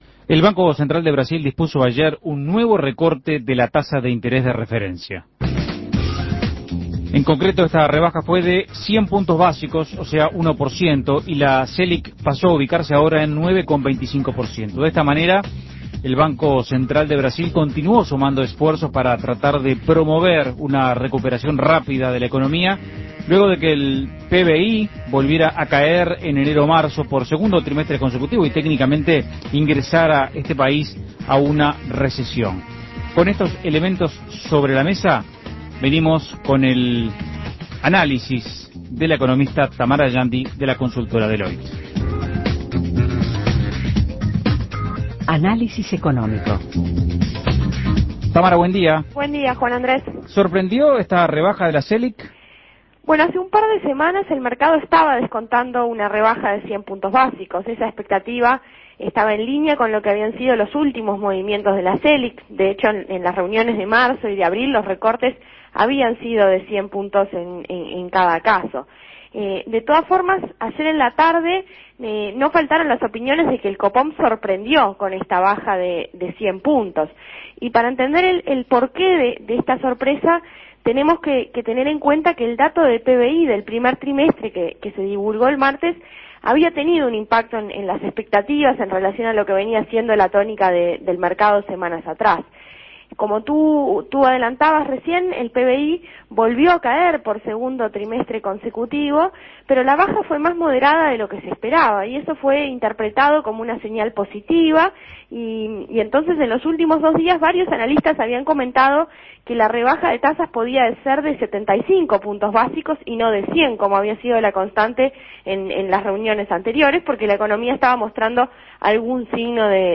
Análisis Económico ¿Cuál es el panorama económico en Brasil?